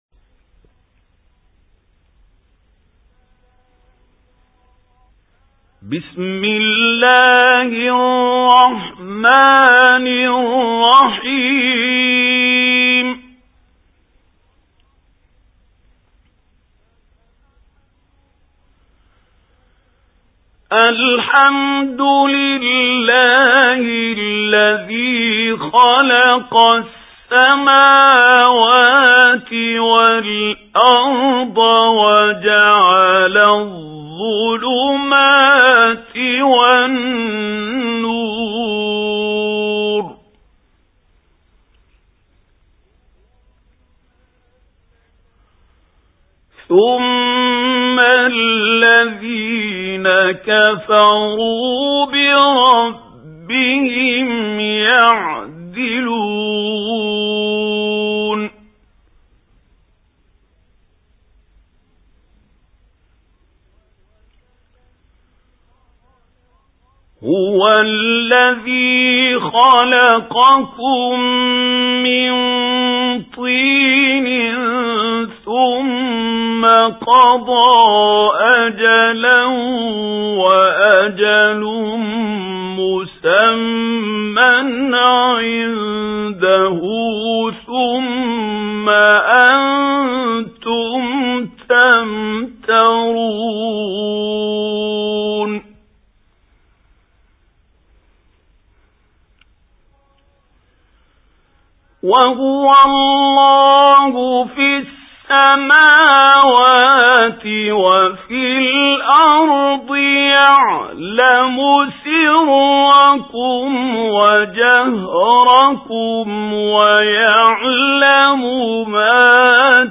سُورَةُ الأَنۡعَامِ بصوت الشيخ محمود خليل الحصري